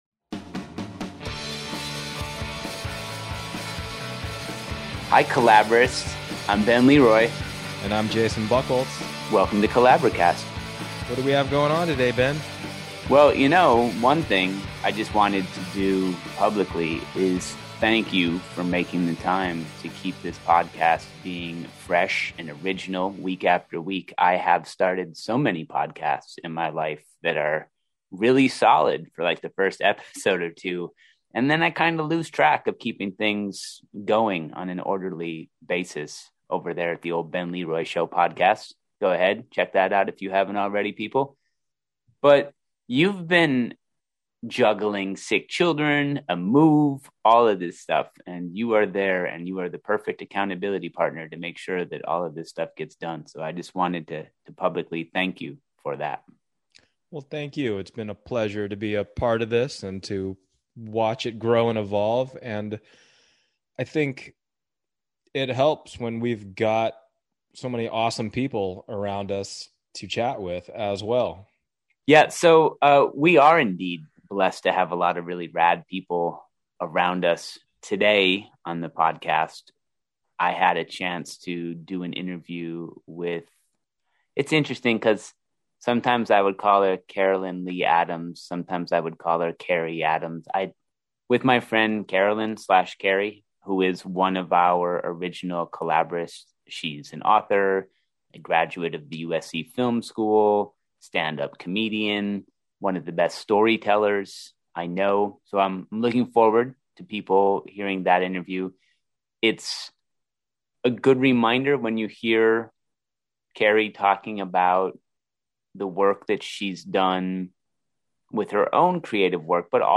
USC Film school, stand up comedy, and novel writing - a conversation